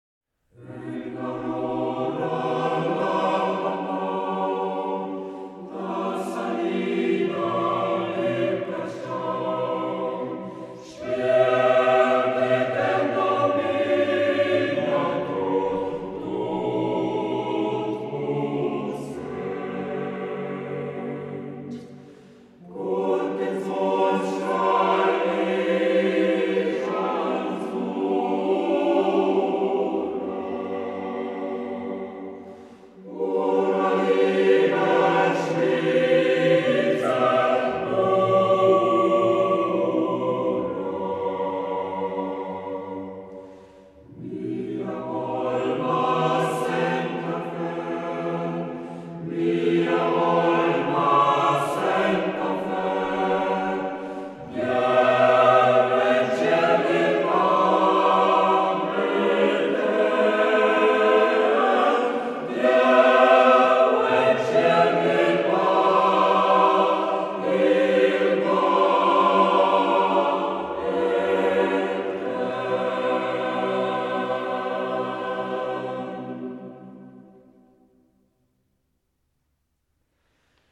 Chor da concert grischun.